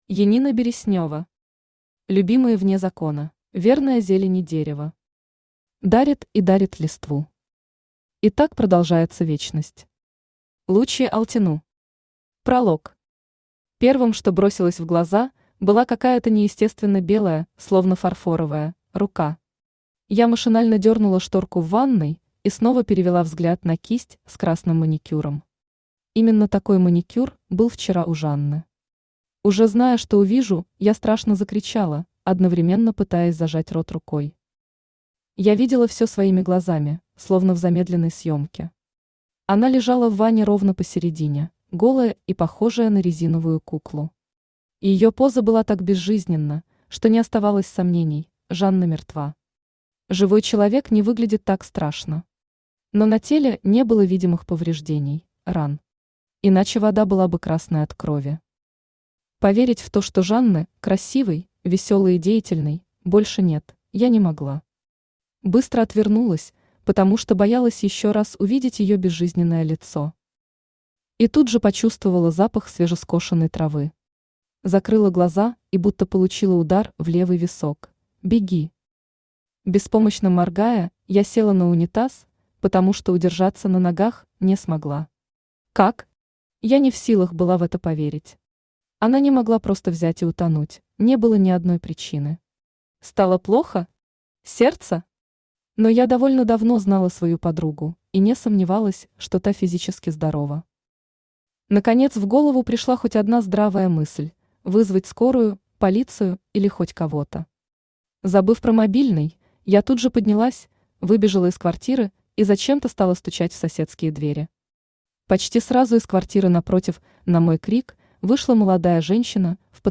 Аудиокнига Любимые вне закона | Библиотека аудиокниг
Aудиокнига Любимые вне закона Автор Янина Олеговна Береснева Читает аудиокнигу Авточтец ЛитРес.